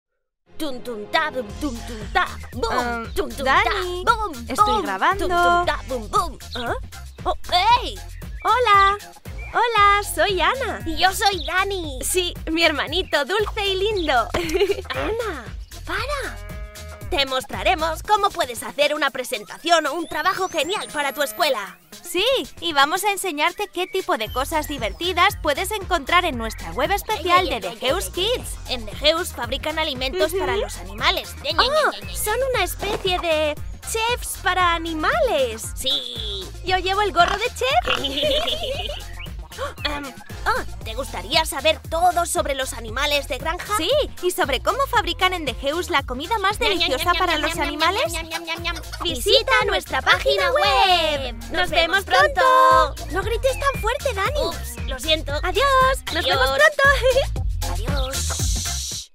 Commercial, Young, Natural, Friendly, Warm
Commercial